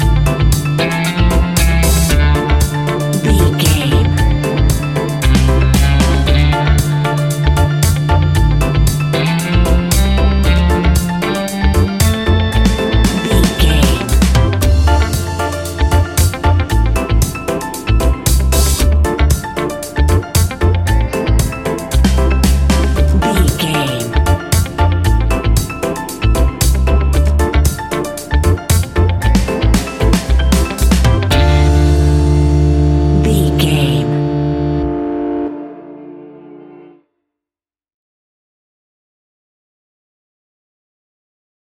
Aeolian/Minor
D
laid back
chilled
off beat
drums
skank guitar
hammond organ
percussion
horns